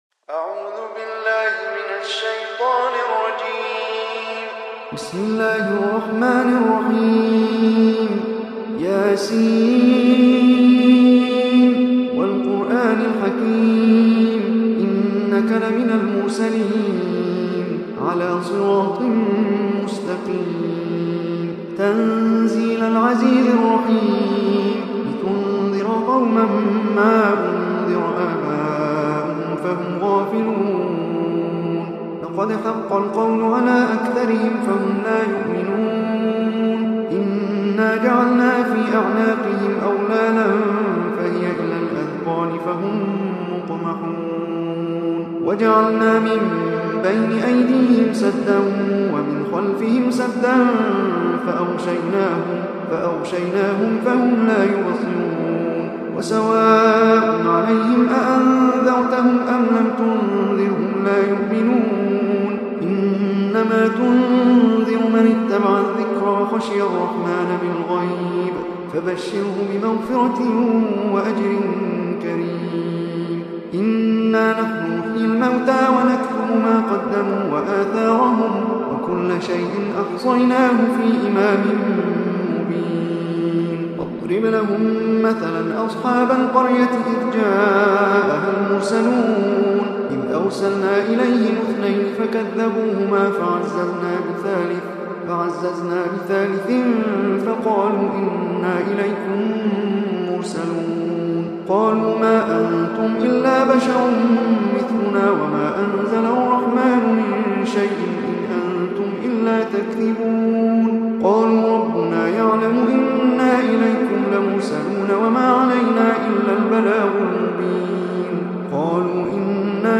tilawat / recitation